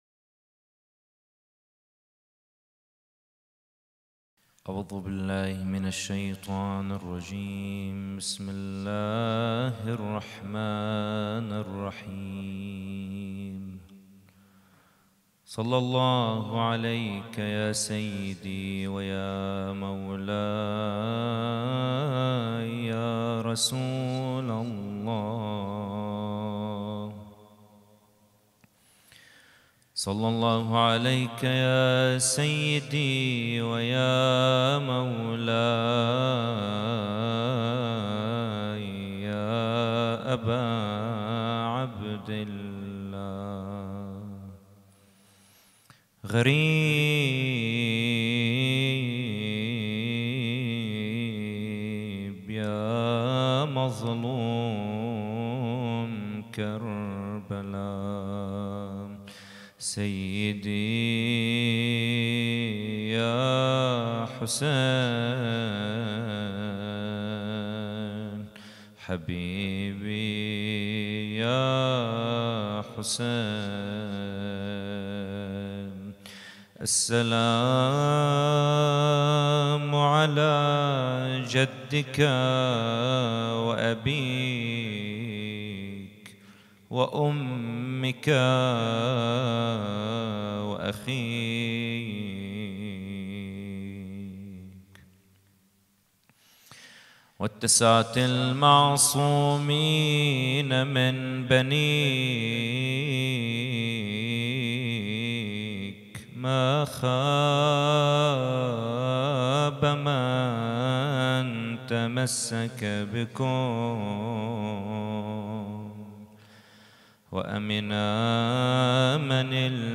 محاضرة
احياء الليلة السابعة من محرم 1442 ه.ق